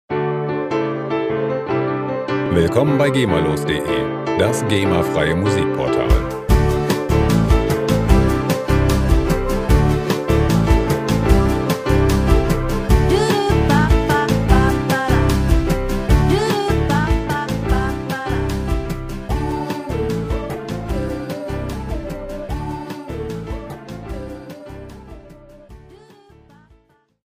Pop Loops GEMAfrei
Musikstil: Pop
Tempo: 150 bpm